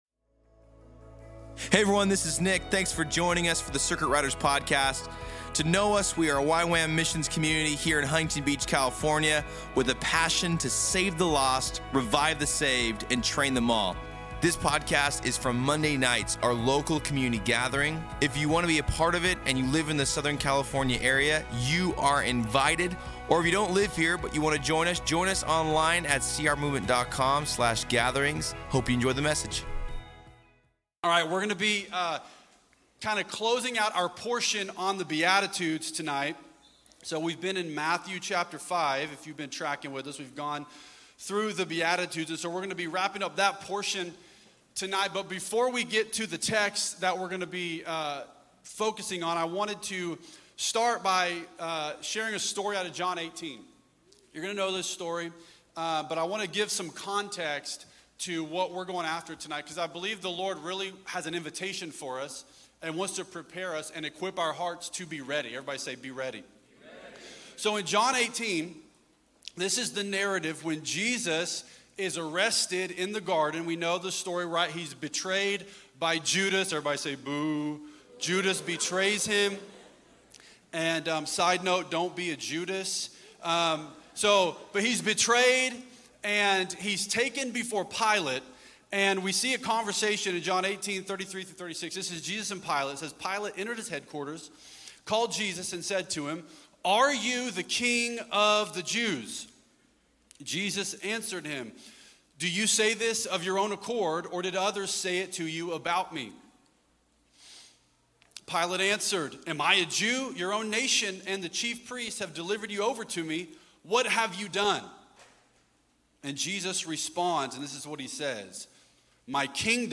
At Circuit Riders Monday Nights.
Location Costa Mesa Topics Circuit , Circuit Riders , God , Jesus , John 18 , Matthew 5:10-16. , Message